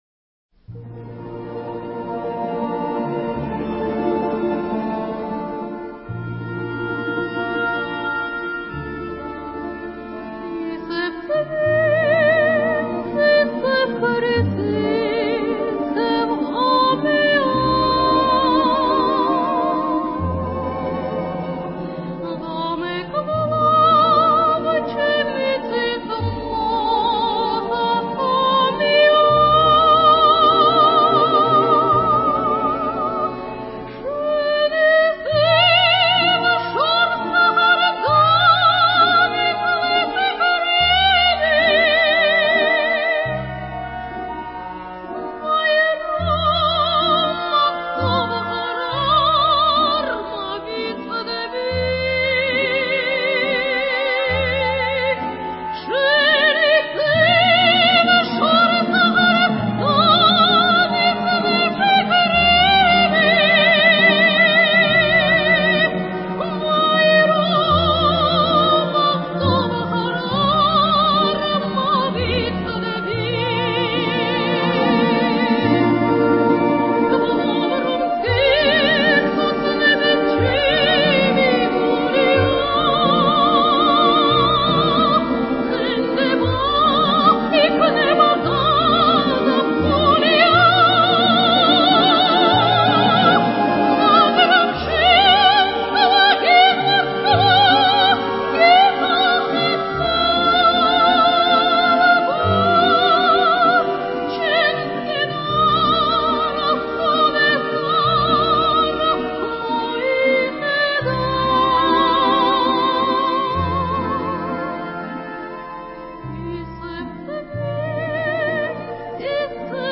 Камерные произведения